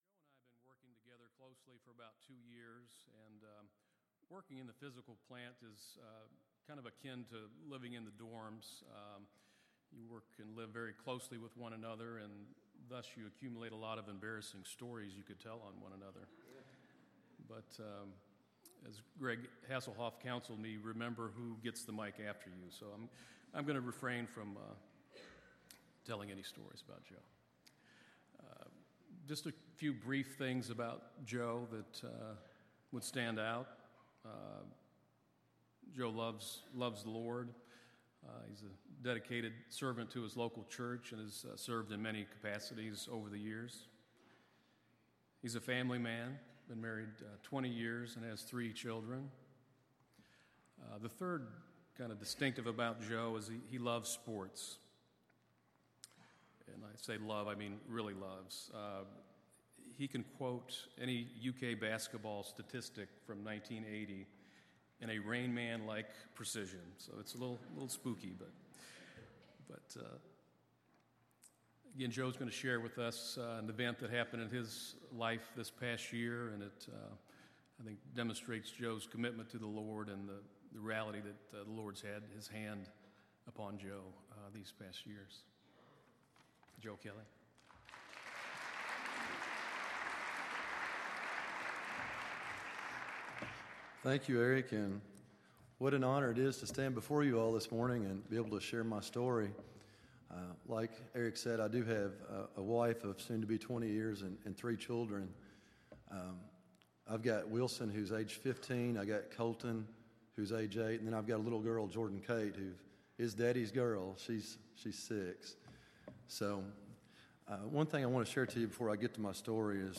“Staff Testimony Chapel”